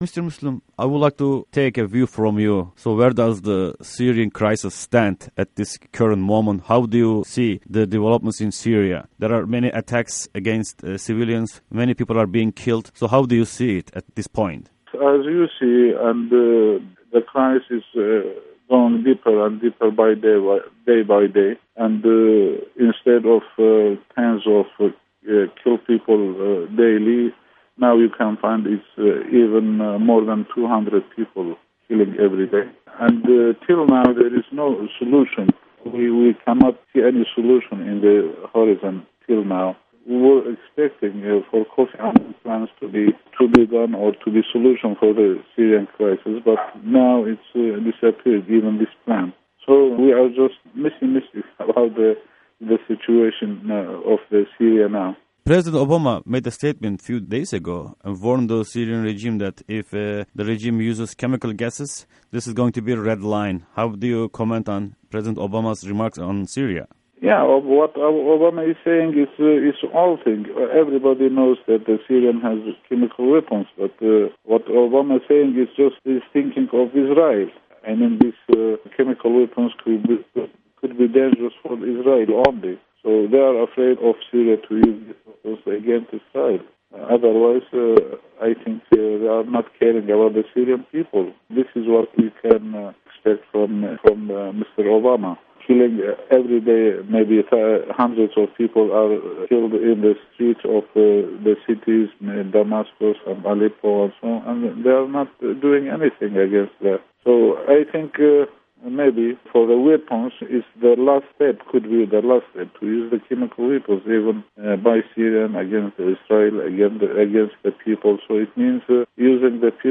Interview_Salih_Muslim_Eng_MC